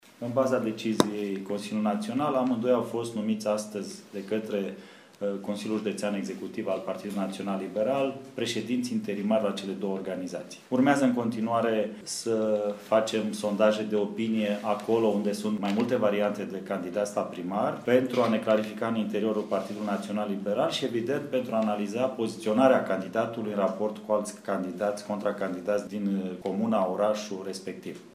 Declaraţia a fost făcută în cadrul unei conferinţe de presă de către co-preşedintele PNL Caraş-Severin, Marcel Vela: